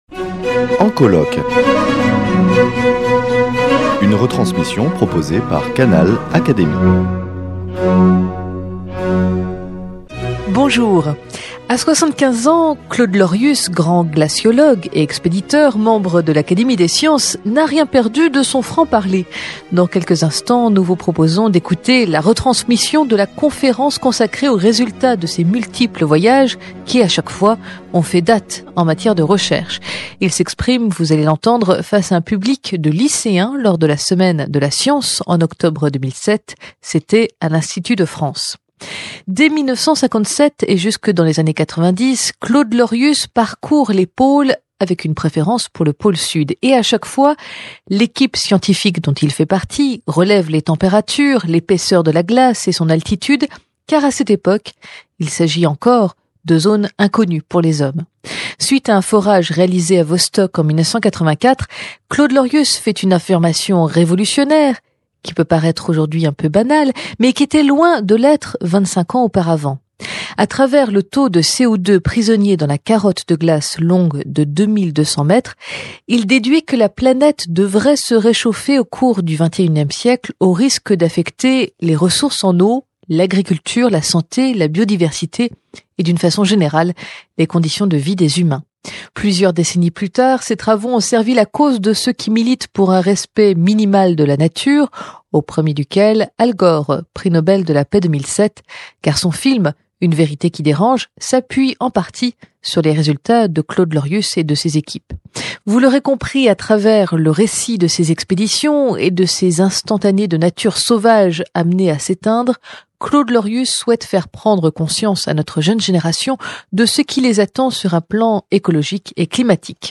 Claude Lorius, éminent glaciologue, revient sur ses principales expéditions : de la station Charcot à l’opération Vostok (en pleine guerre froide) en passant par un raid en Terre de Victoria, il profite de son public de lycéens pour insister sur les impacts de la pollution sur la fonte des glaces continentales et des mers, modifiant dangereusement notre écosystème.